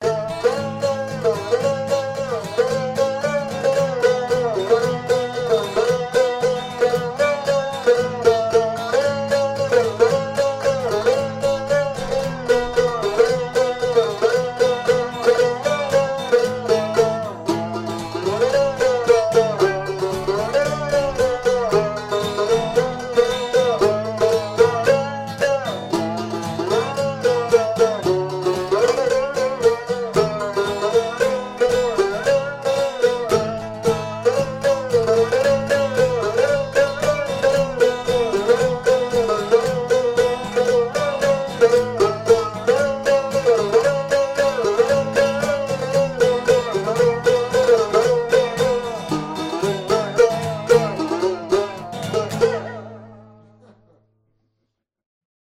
gtr